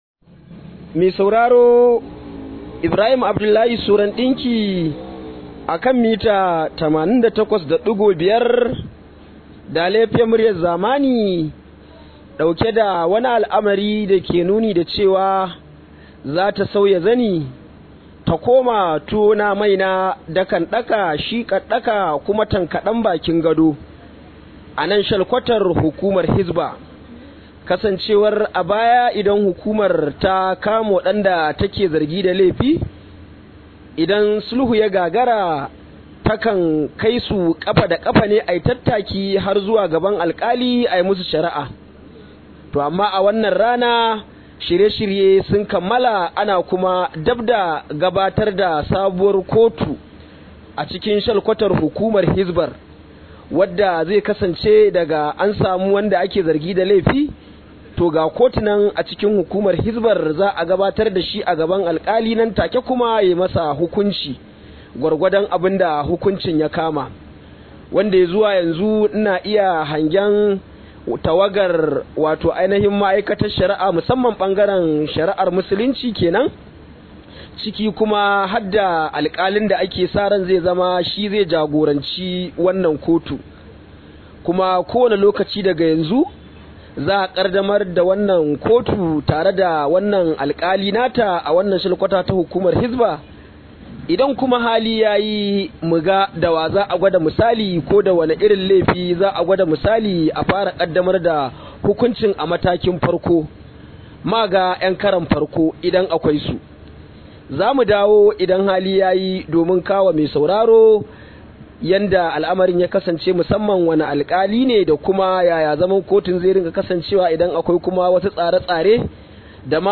Rahoto: Kotun shari’ar musulunci ta fara aiki a Hisba